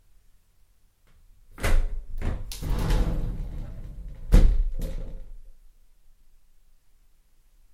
Shower Door Open
Duration - 7s Environment - Inside very small bathroom fully tiled. Description - Door slides quickly open, rails, opens with bang.